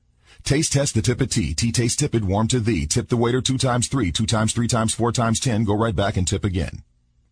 tongue_twister_05_03.mp3